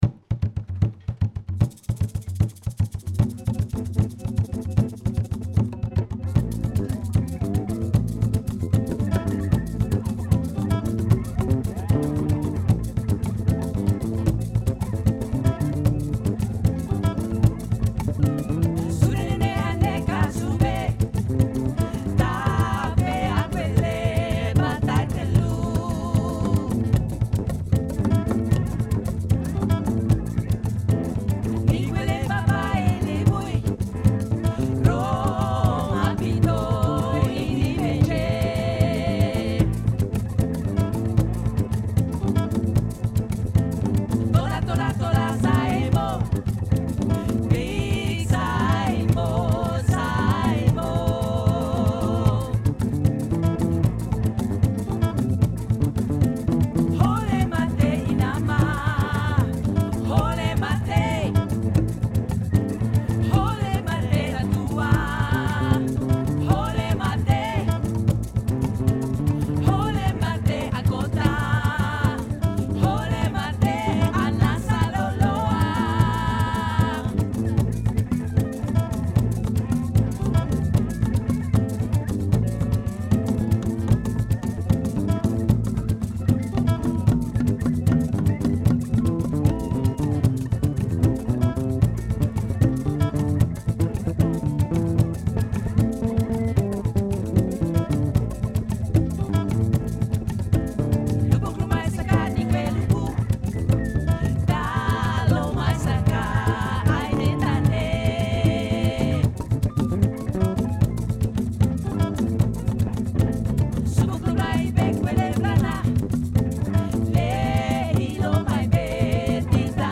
etno-jazz
concertzaal
• ‘Alles wordt gespeeld met een keizerlijke flair ‘.